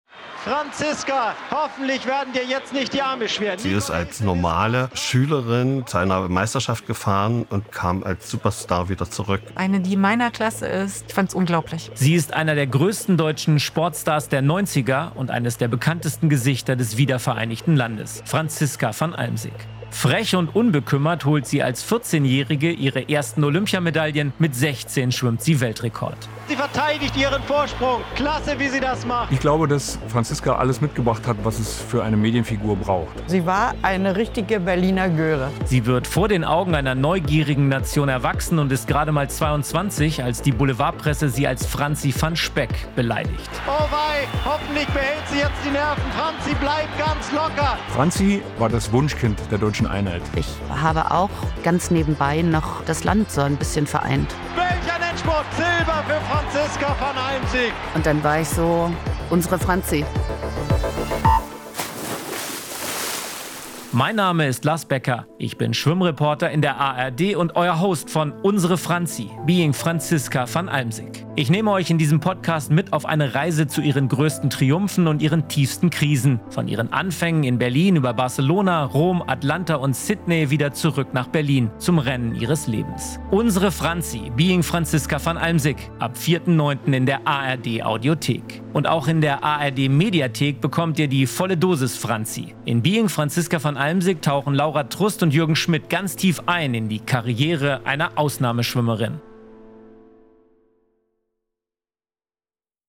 Trailer: "Unsere Franzi - Being Franziska van Almsick"